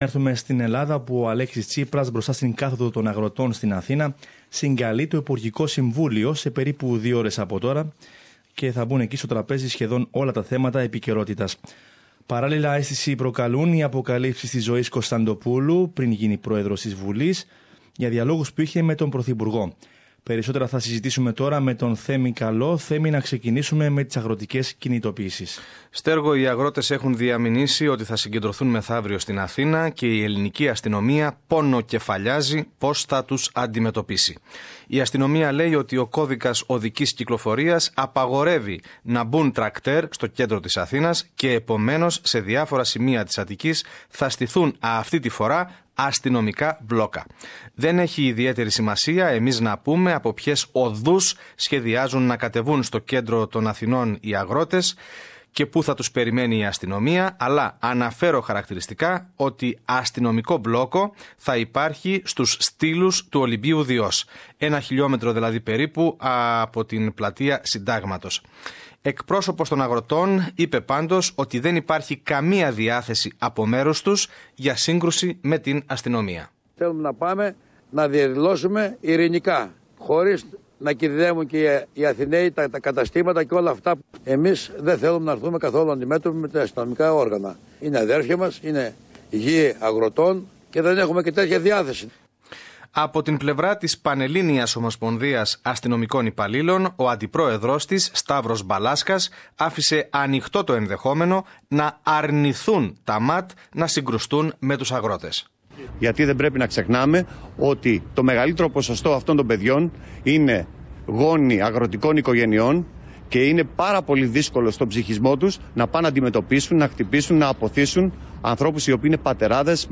The government is hoping that it will be able to convince protesting farmers, who are planning to demonstrate in Athens on Friday, to negotiate over their objections to tax and pension reform proposals. More in this report